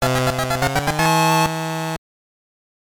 Fanfare that plays when revealing a solved puzzle.